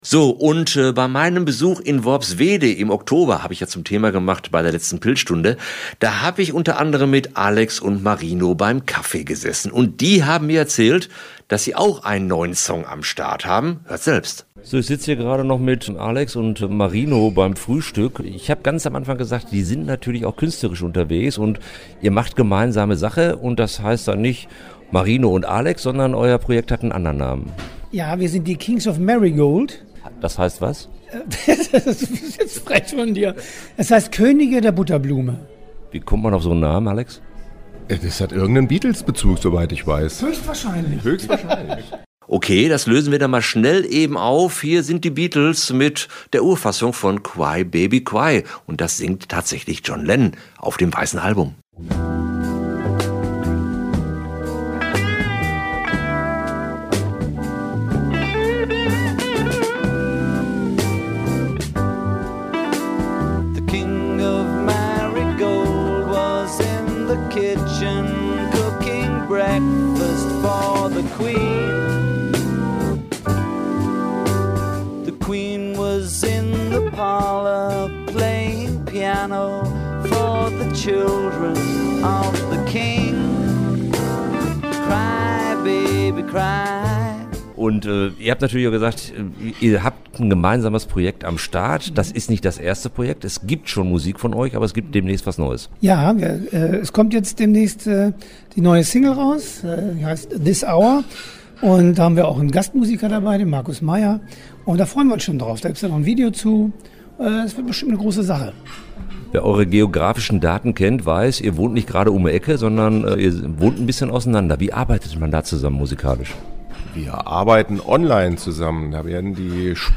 sprach mit dem Duo in Worpswede